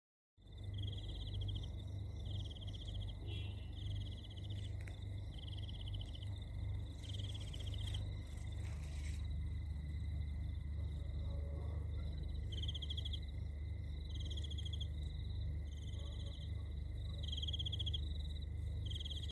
Cricket Bouton sonore